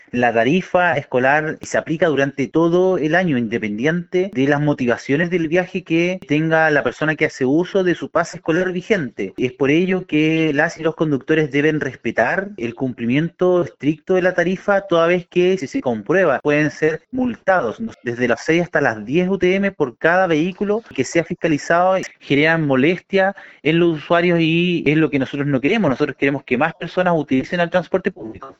Al respecto, el seremi de Transportes, Pablo Joost, afirmó que se les notificó hace unos días a los representantes legales de las empresas que se debe respetar la rebaja tarifaria a estudiantes, independiente del motivo y fecha de su viaje.